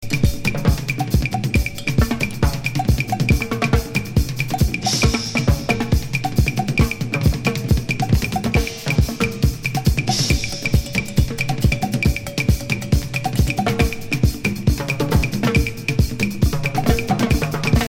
afro-funk
hi-octane percussion workout